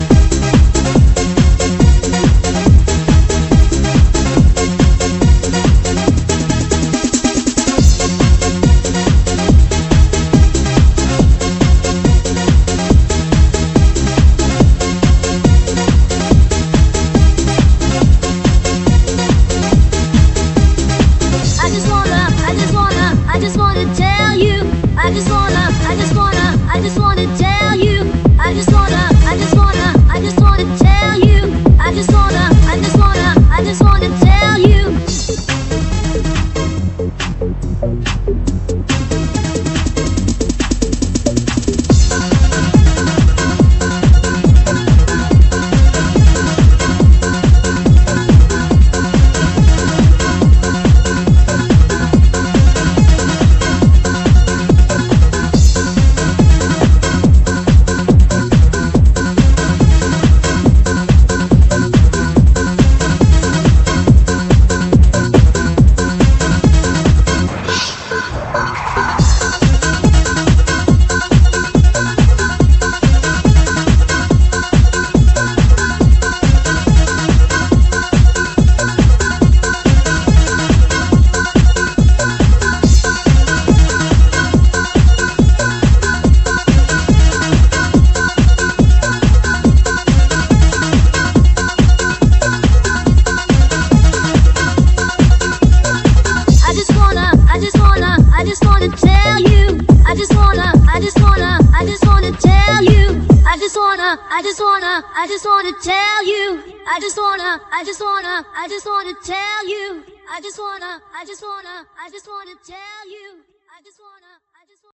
piosenka znaleziona na płycie cd - Muzyka elektroniczna